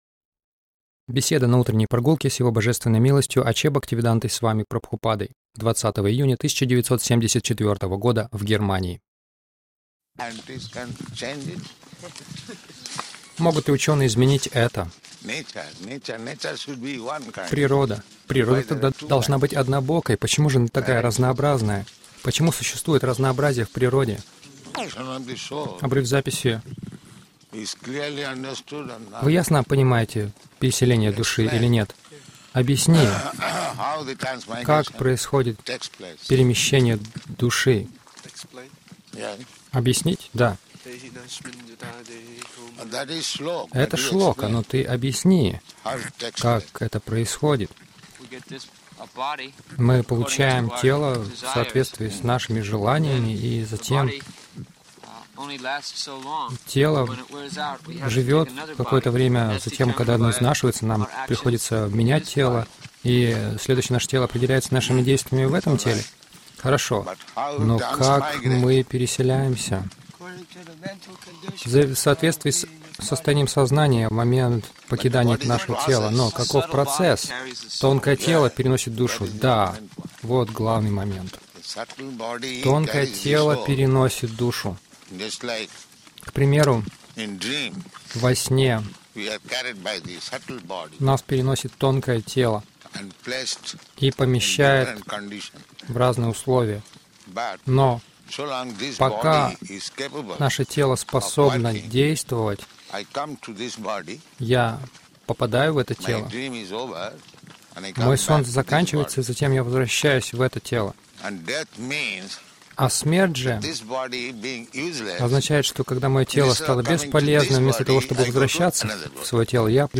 Утренняя прогулка с учениками, а также беседа в машине —